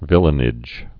(vĭlə-nĭj)